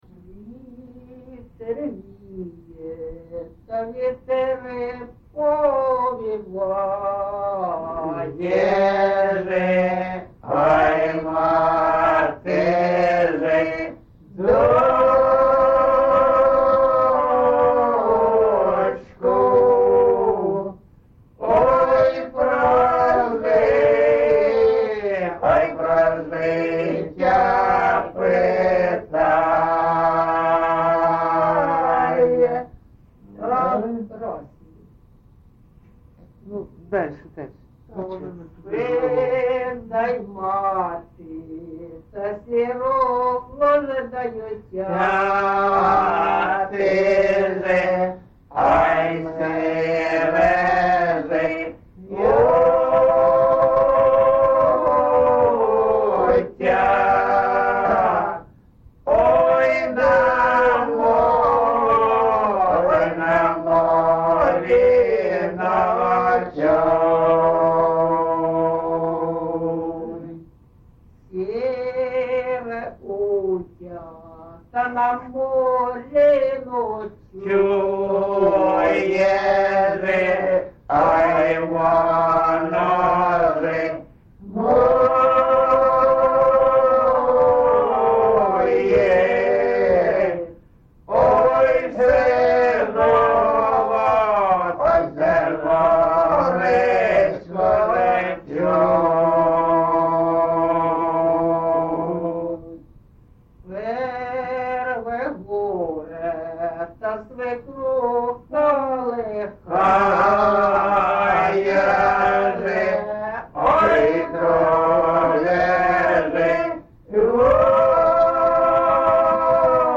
ЖанрПісні з особистого та родинного життя
МотивНещаслива доля, Родинне життя, Журба, туга
Місце записус. Маринівка, Шахтарський (Горлівський) район, Донецька обл., Україна, Слобожанщина